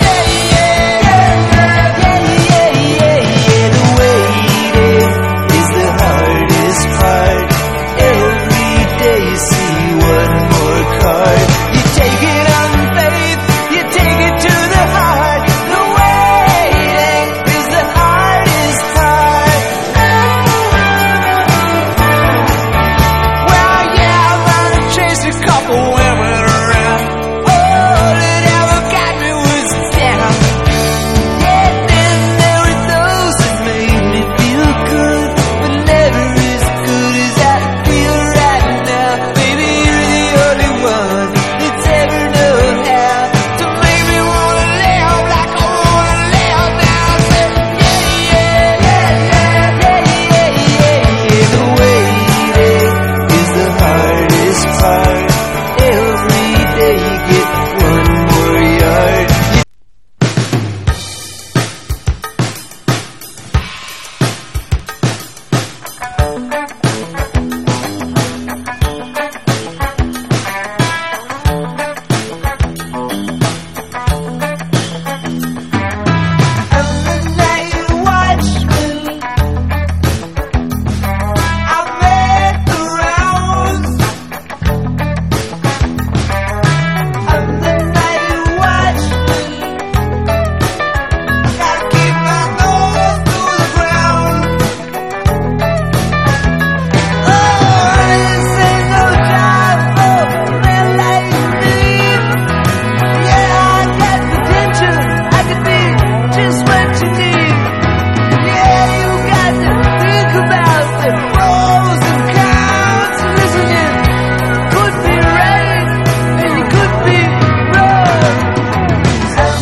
¥3,880 (税込) ROCK / 80'S/NEW WAVE.
DRUM BREAK